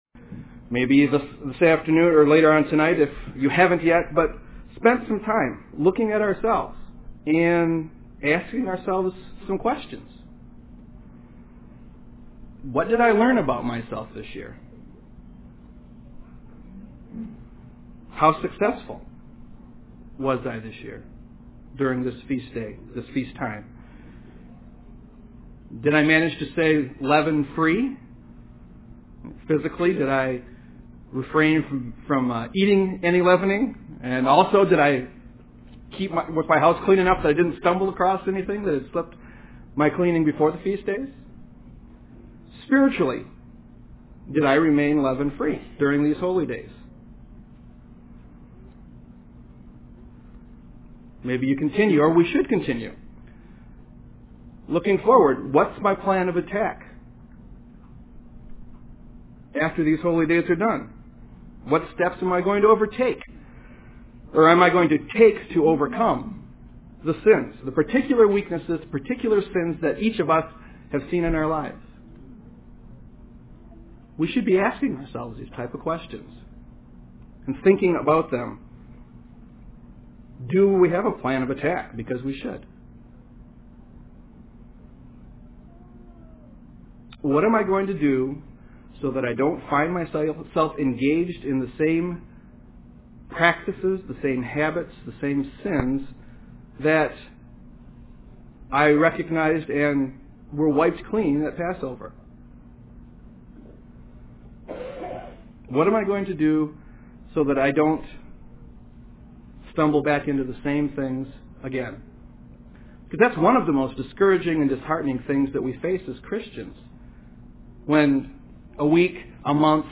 The Feast of Unleavened Bread is to help teach us lessons about sin and coming out of sin, Egypt being the type of sin we need to come out of. This message was given on the Last Day of Unleavened Bread.
Given in Grand Rapids, MI
UCG Sermon Studying the bible?